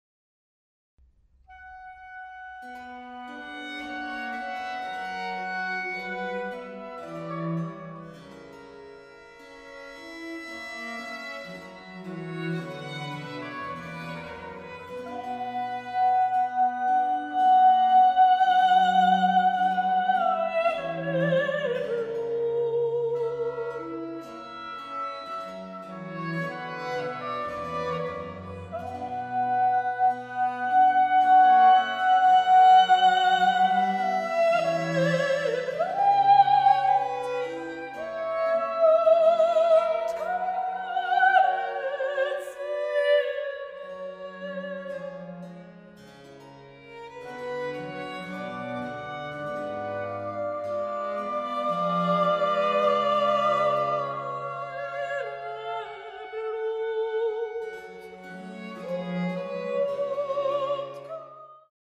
Arie